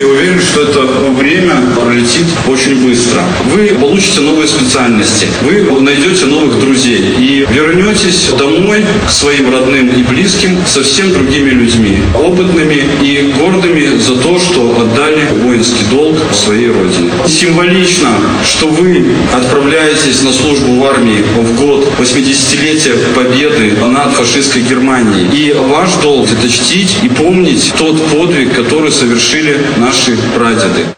В Барановичах состоялся День призывника. Традиционное мероприятие развернулось в стенах городского Дома культуры.
Как подчеркнул заместитель председателя горисполкома Вадим Щербаков, впереди у парней интересное время, насыщенное новым и очень важным опытом.